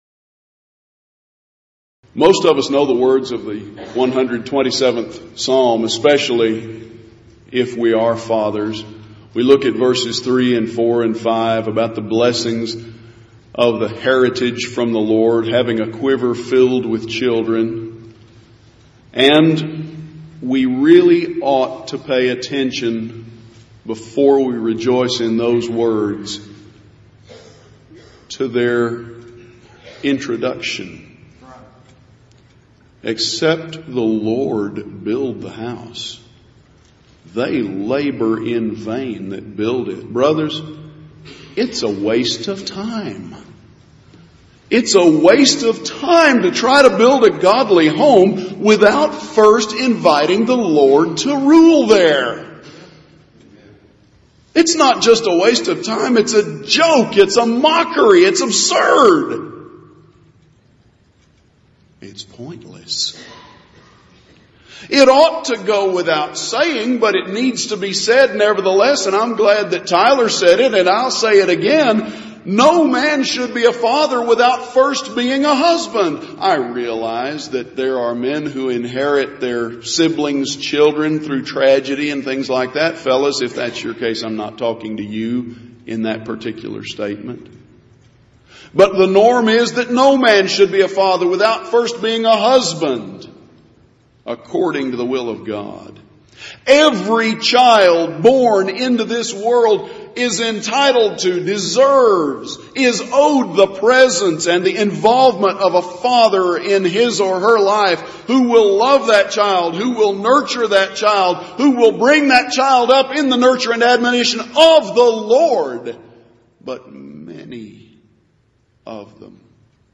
Event: 28th Annual Southwest Lectures
lecture